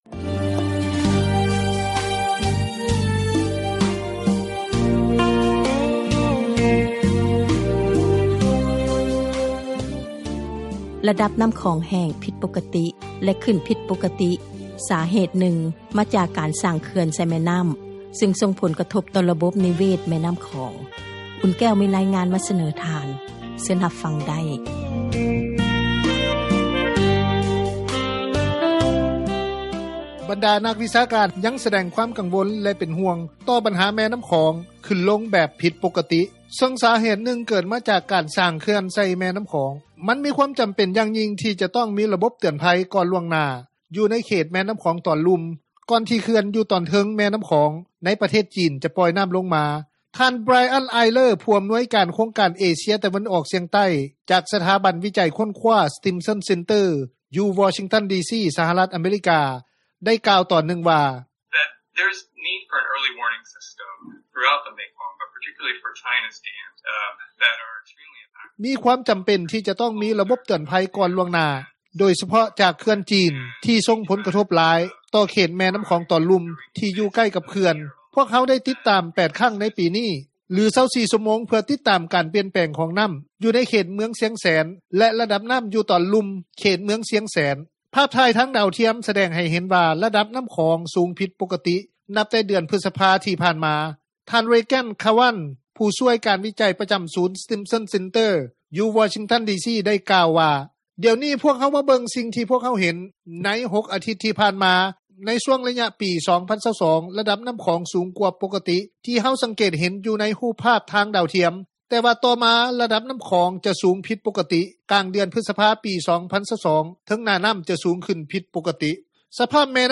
ຊາວບ້ານ ຢູ່ເມືອງຊະນະຄາມ ແຂວງວຽງຈັນ ທ່ານນຶ່ງ ກ່າວວ່າ: